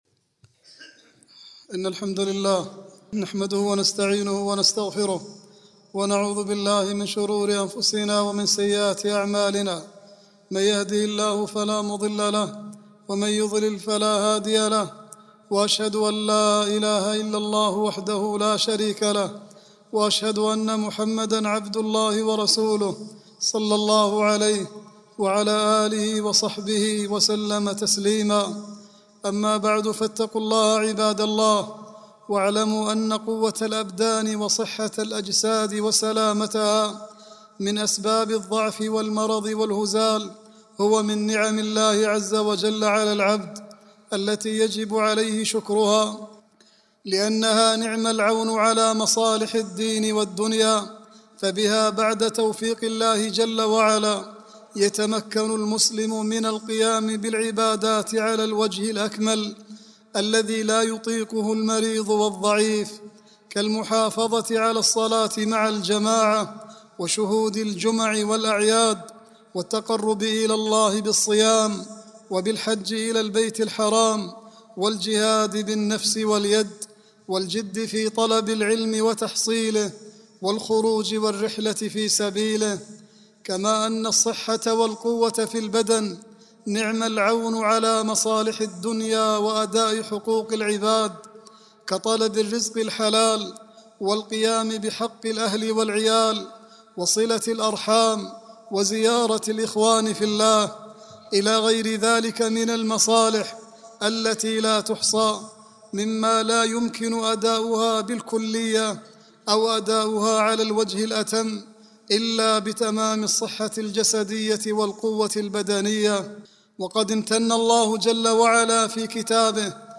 التعصب الرياضي خصلة جاهلية خطبة
khutbah-16-5-39.mp3